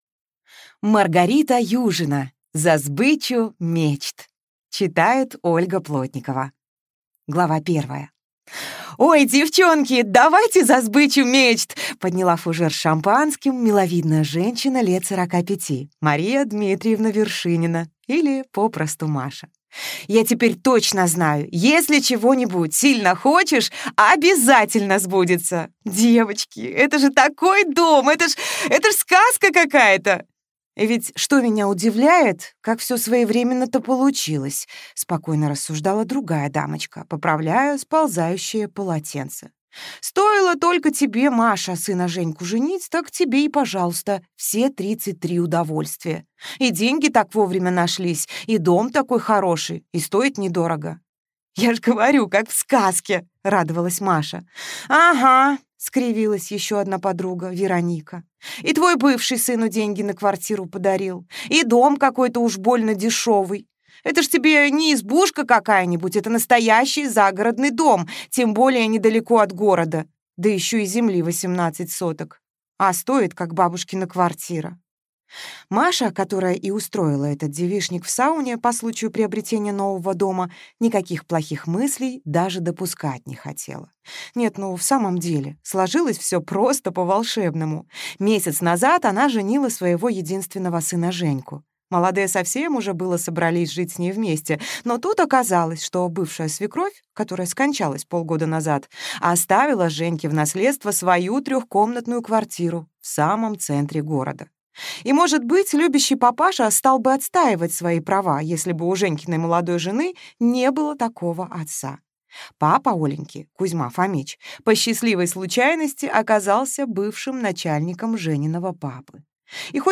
Аудиокнига За сбычу мечт | Библиотека аудиокниг